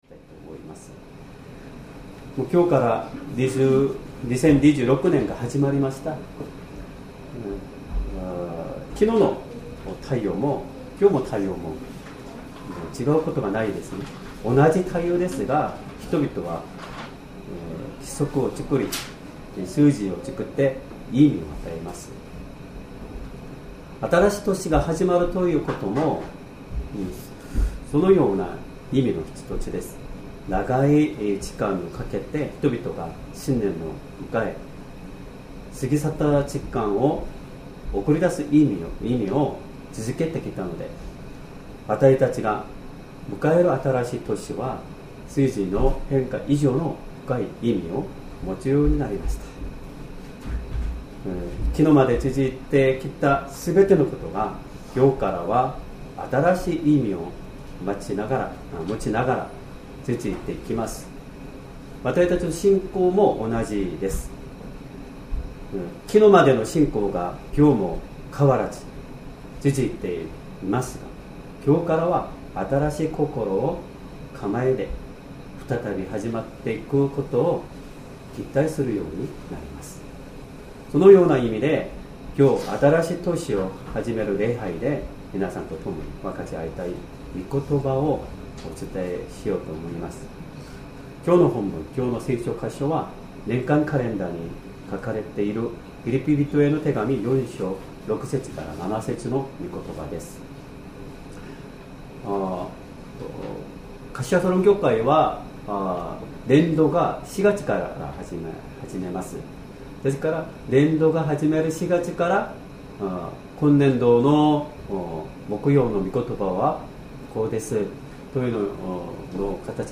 Sermon
2026年1月1日 新年礼拝 説教 「神の平安が守ってくれます 」 聖書 ピリピ人への手紙 4章 6-7節 4:6 何も思い煩わないで、あらゆる場合に、感謝をもってささげる祈りと願いによって、あなたがたの願い事を神に知っていただきなさい。